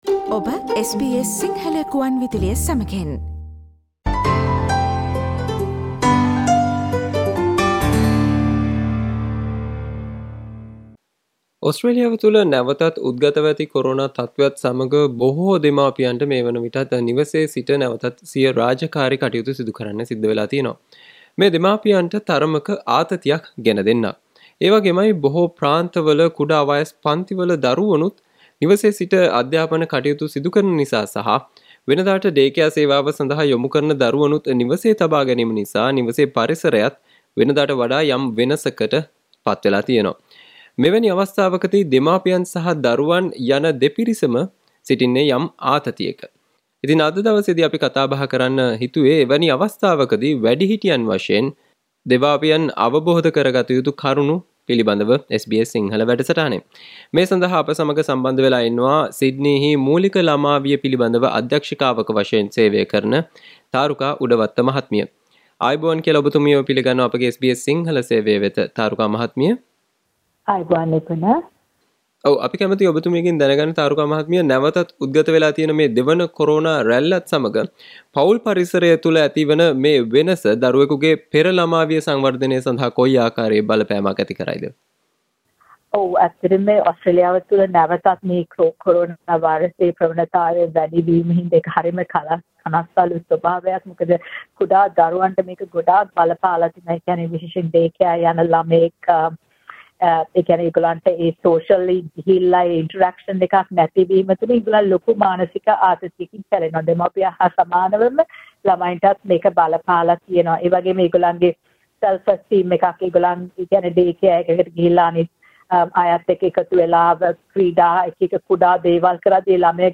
SBS Sinhala Service Discussion on how to deal with your children while working from home